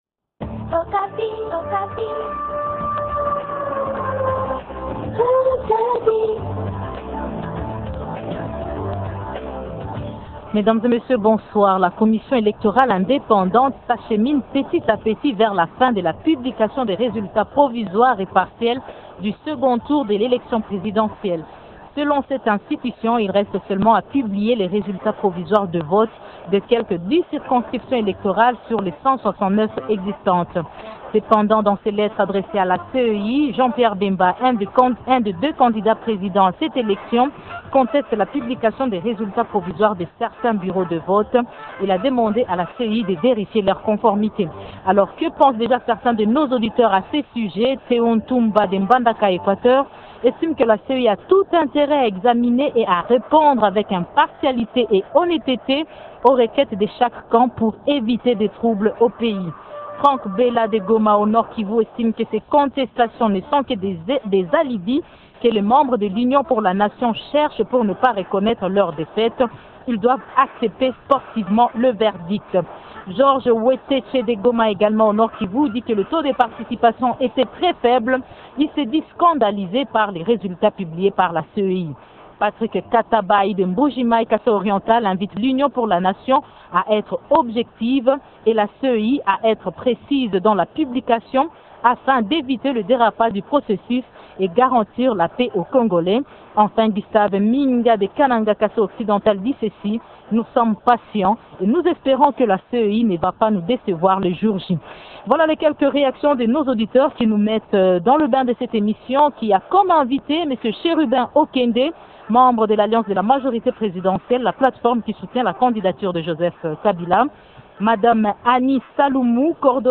Deux questions constituent notre débat de ce soir :